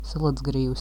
pronunciation
Lv-Salacgrīva.ogg.mp3